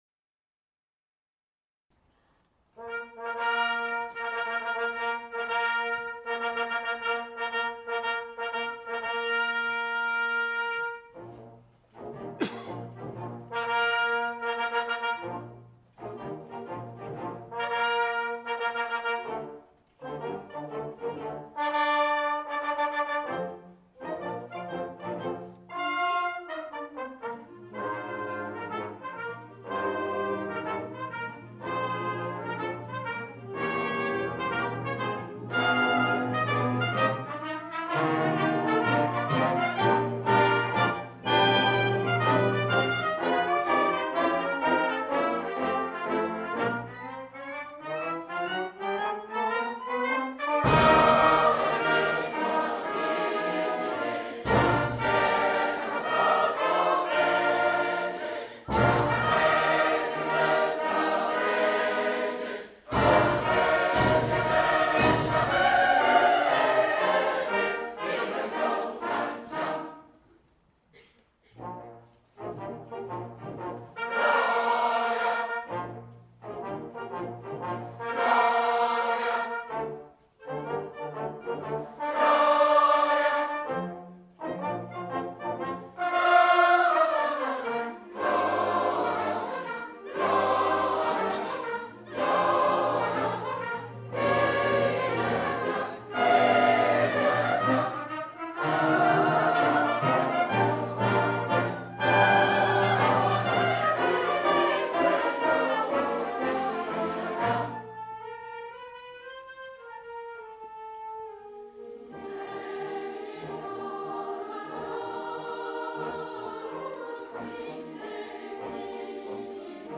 Choeurs en Harmonie
Ce beau et grand projet en 2019 a réuni 150 choristes accompagnés de l'harmonie « les enfants de Chablis ».